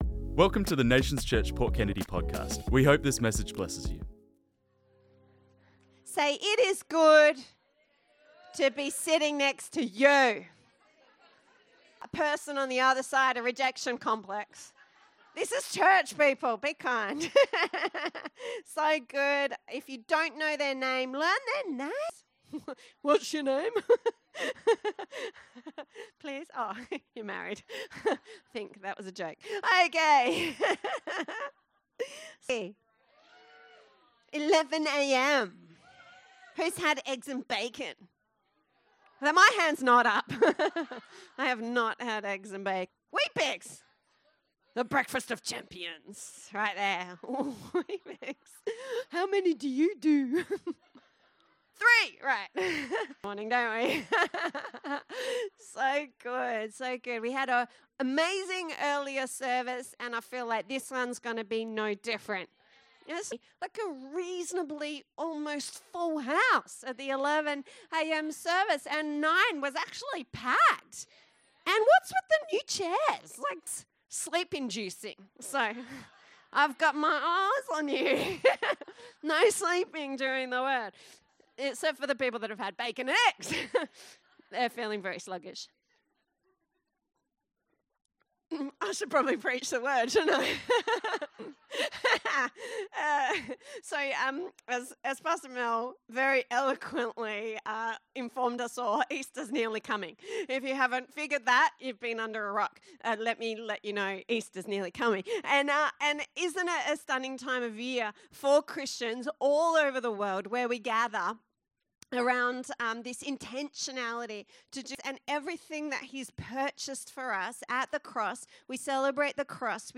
This message was preached on Sunday 6th April 2025, by guest speaker